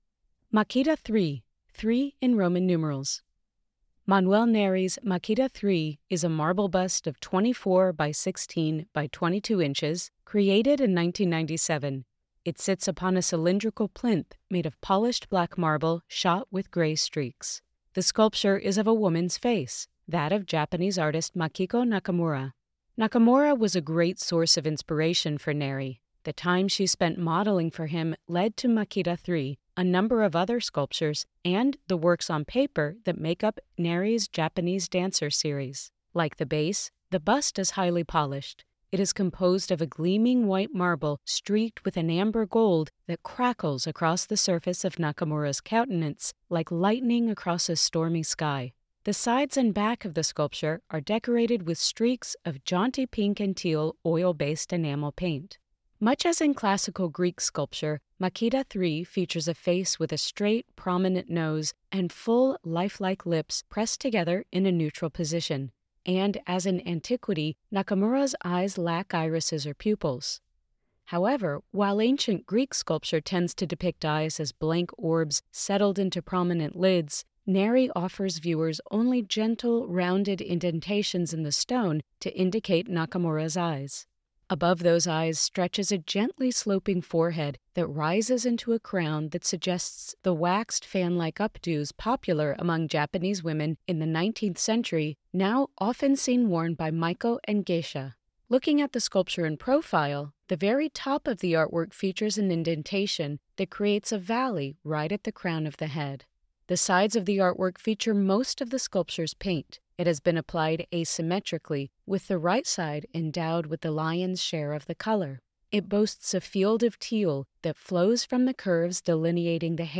Audio Description (02:32)